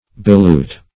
Search Result for " belute" : The Collaborative International Dictionary of English v.0.48: Belute \Be*lute"\ (b[-e]*l[=u]t"), v. t. [imp.